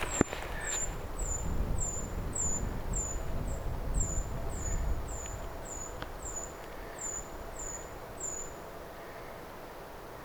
tätä voisi kutsua ti-siritykseksi,
koska se koostuu kuin ti-äänistä
voi_kai_sanoa_ti-siritysta_ti-aanista_koostuvaa_puukiipijalinnun_siritysaantelya.mp3